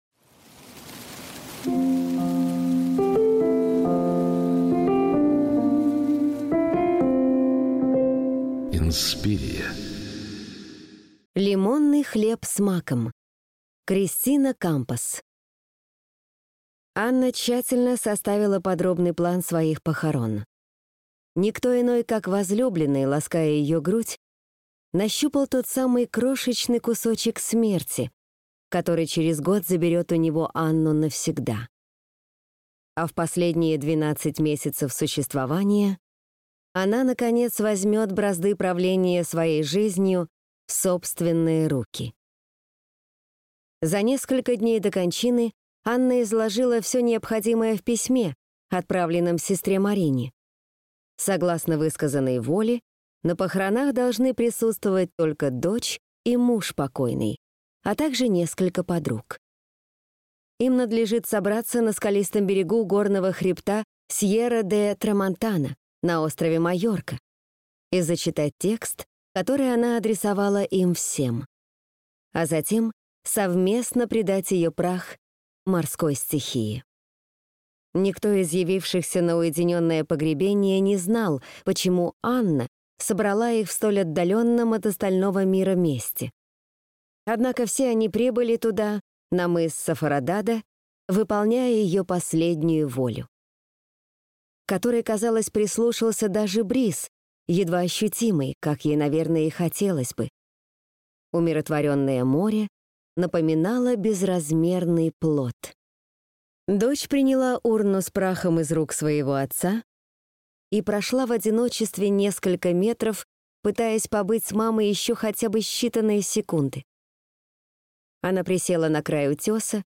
Аудиокнига Лимонный хлеб с маком | Библиотека аудиокниг